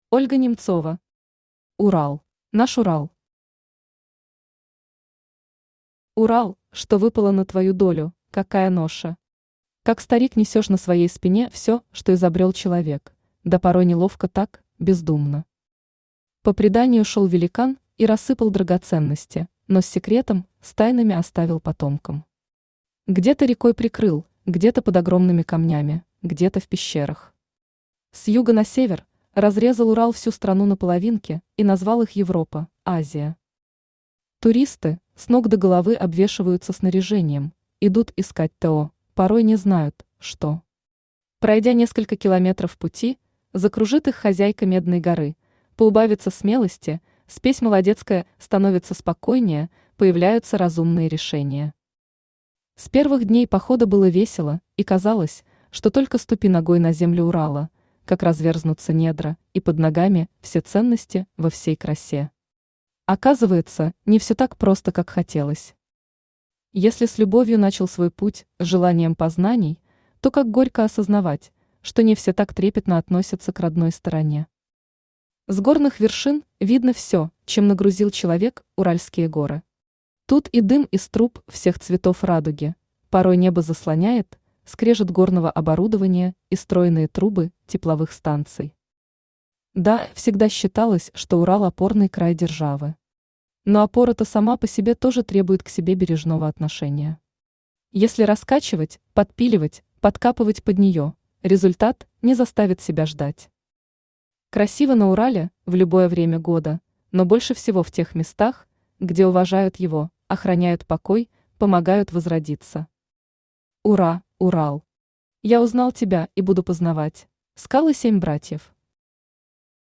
Аудиокнига Урал | Библиотека аудиокниг
Aудиокнига Урал Автор Ольга Максимовна Немцова Читает аудиокнигу Авточтец ЛитРес.